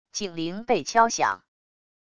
警铃被敲响wav音频